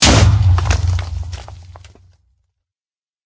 explode3.ogg